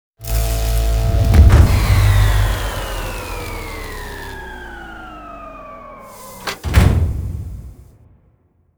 poweroff.wav